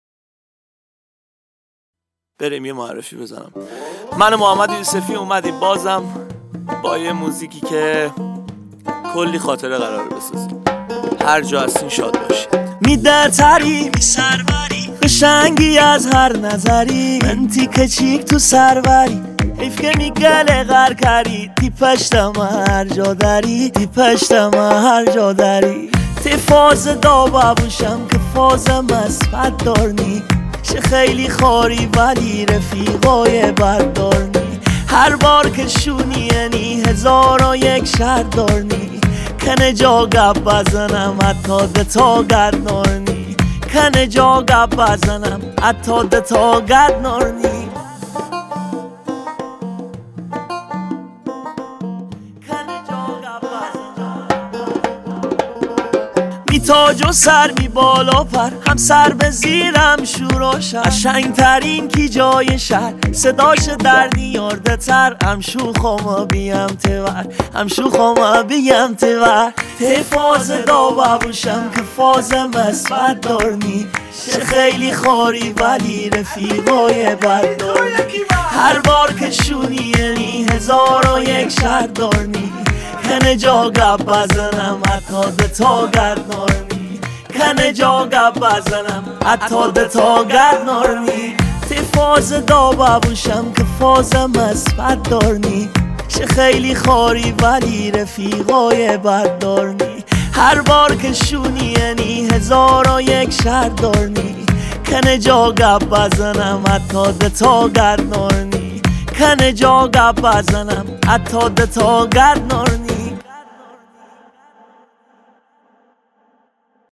ریتمیک ( تکدست )
با صدای خواننده خوش صدای شمالی
آهنگی در سبک آهنگ های ریتمیک مازندرانی